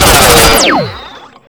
rifle2.wav